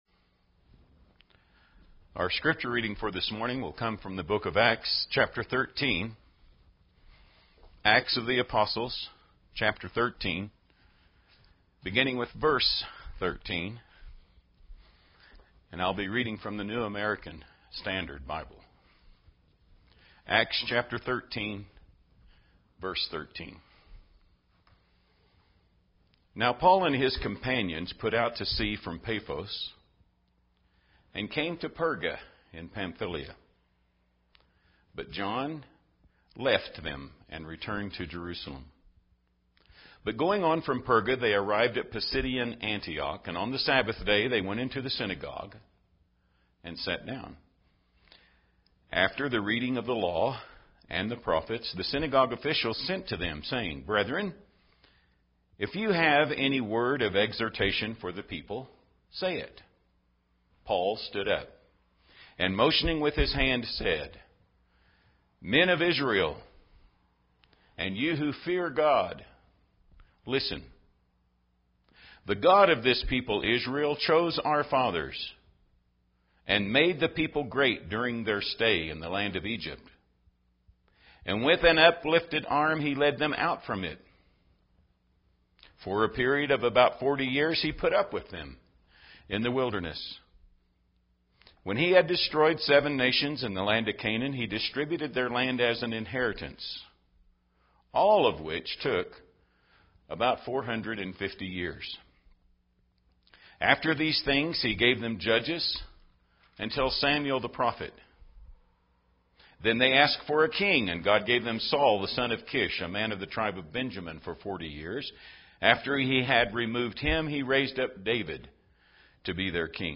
A Synagogue Sermon